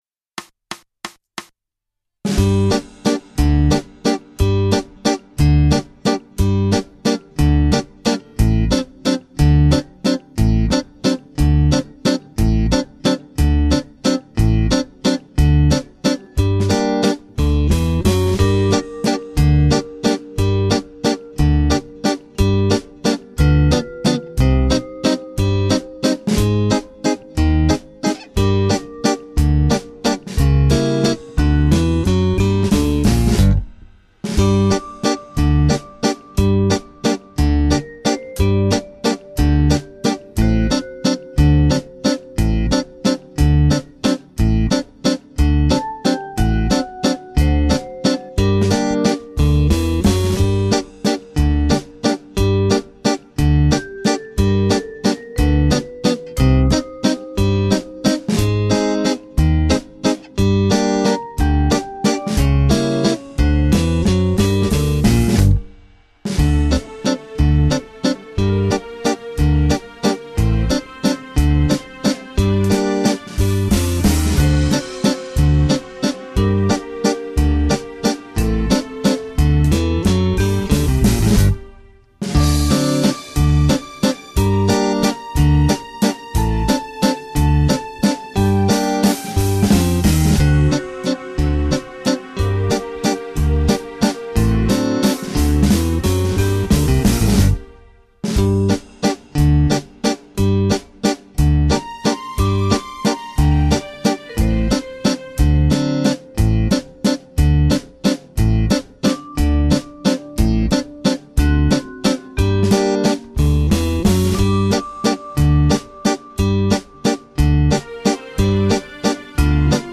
Genere: Valzer
Scarica la Base Mp3 (3,01 MB)